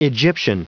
Prononciation du mot egyptian en anglais (fichier audio)
Prononciation du mot : egyptian